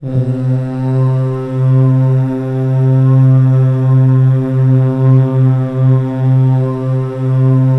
DRONER    -R.wav